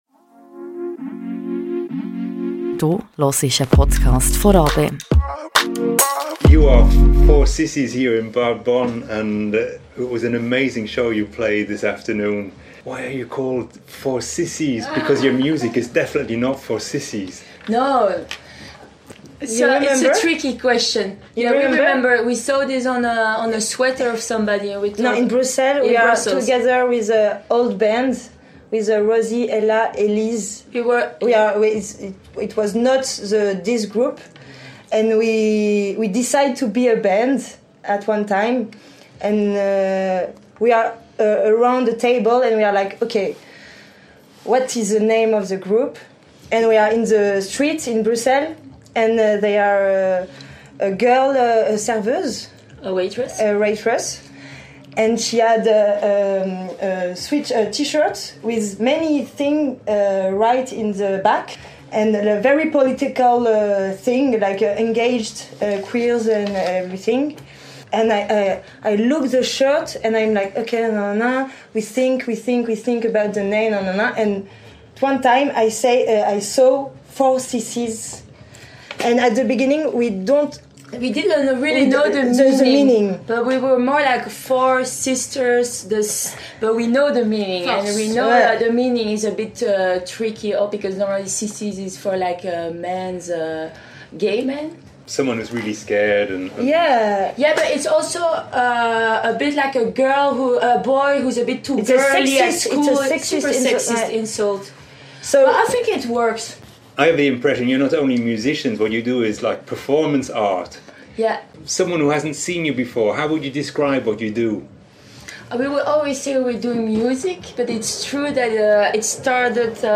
Amplifier-Interview with Forsissies ~ Radio RaBe Podcast
Forsissies (466) are a powerful feminist live-act from Belgium. After a strong performance at the Bad Bonn Kilbi 2025 we met for a sprawling conversation.